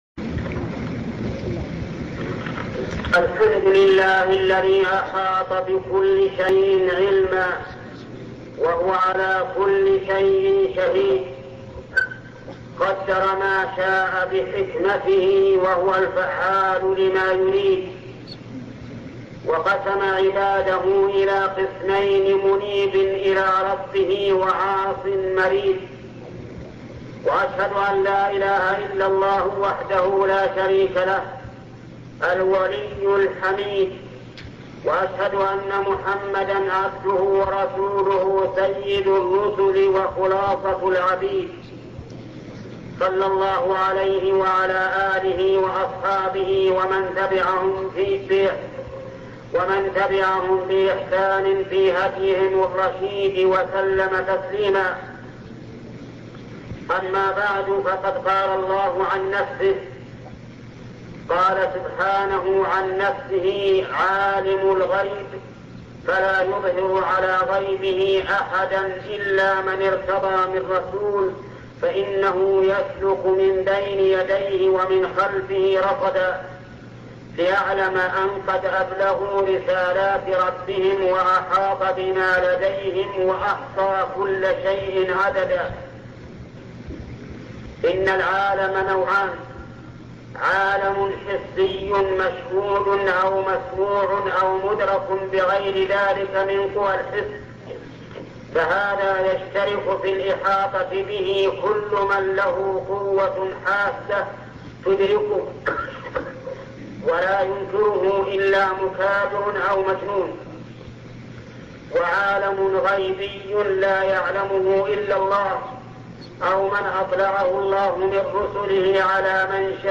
الشيخ محمد بن صالح العثيمين خطب الجمعة